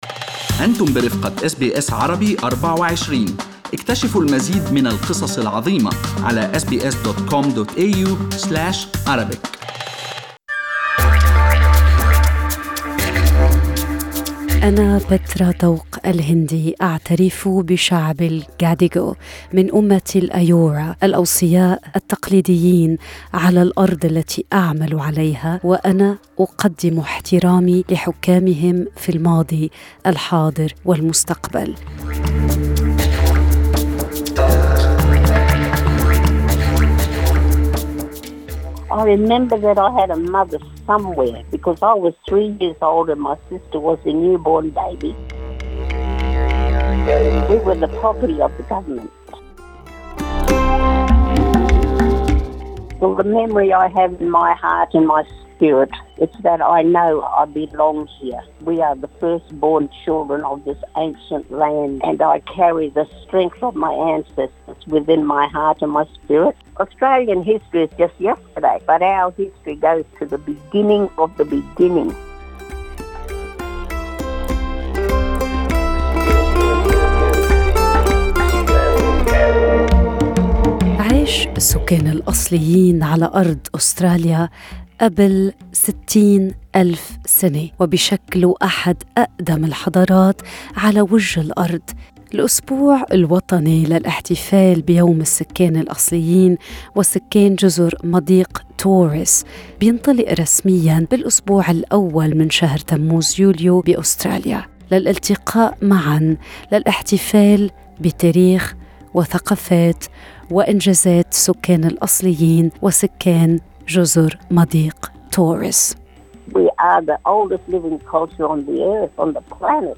وغنّت بصوتها